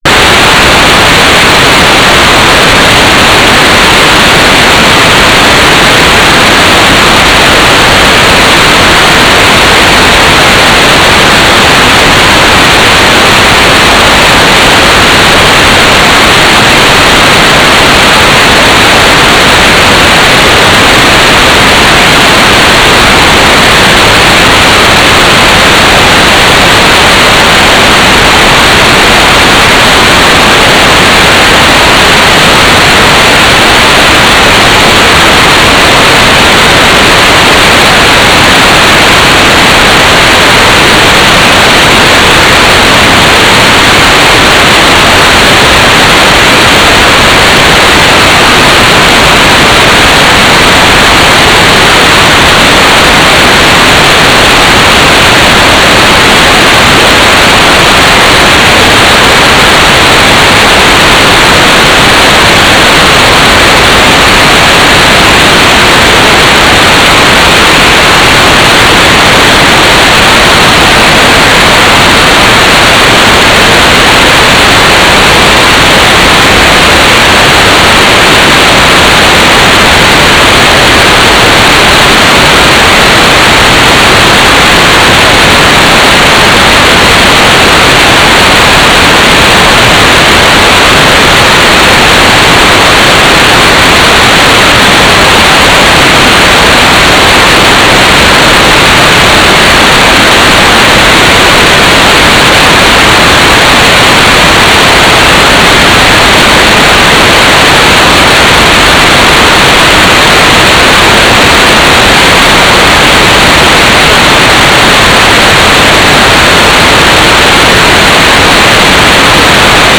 "transmitter_description": "Mode U - GMSK4k8 - TLM, CAM, Gyro",